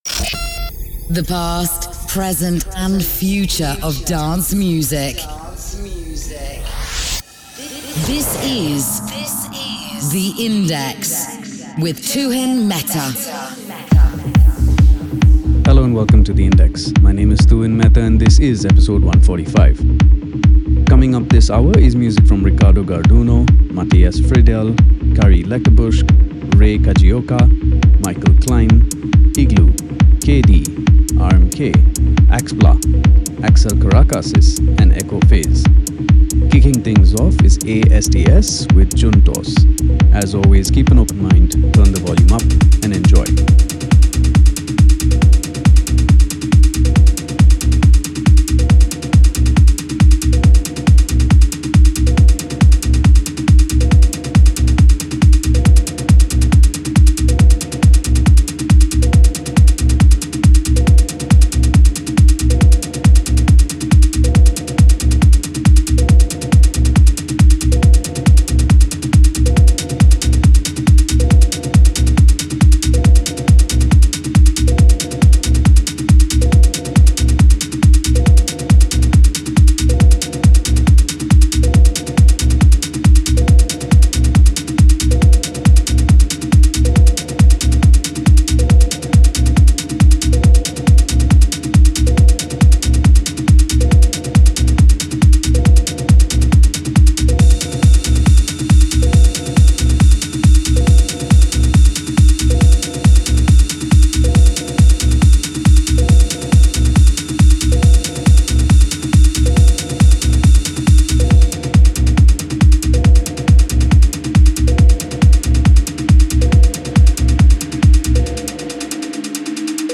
The past, present & future of dance music